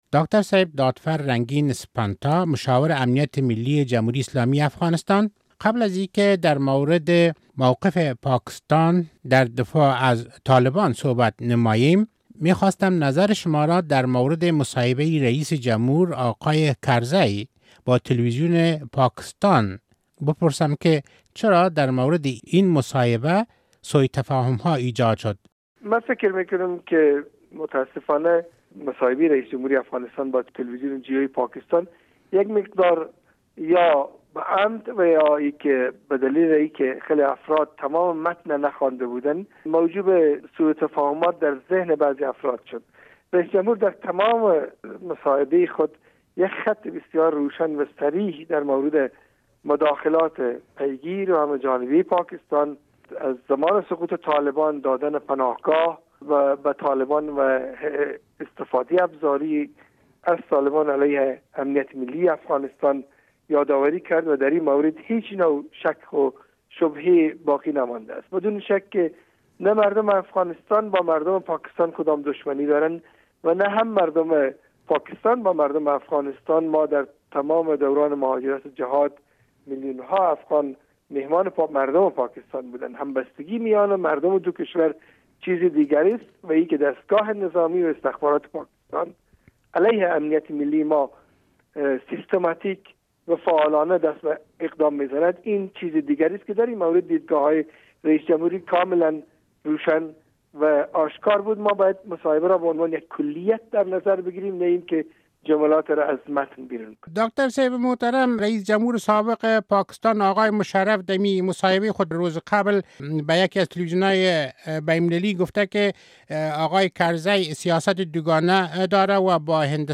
مصاحبه با رنگین دادفر سپنتا در مورد روابط افغانستان و پاکستان